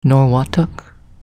Norwottuck pronunciation only